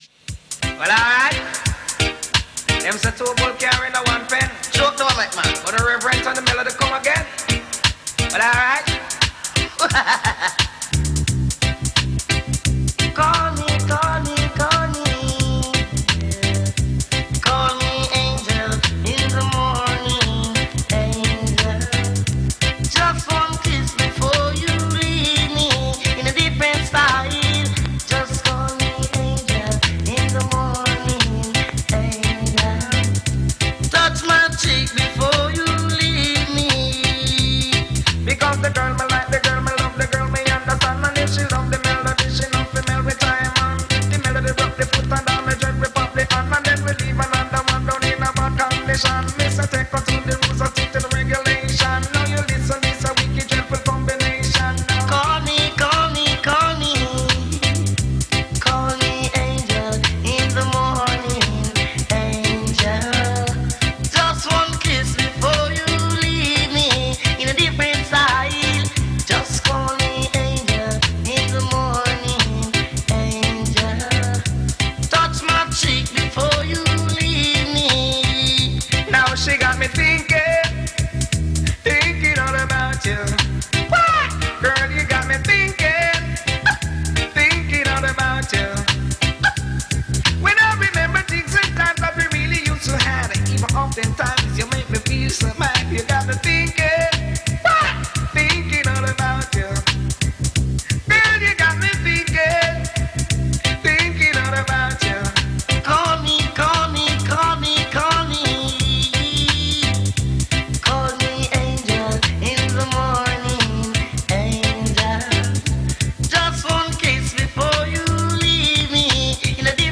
1980s juggling.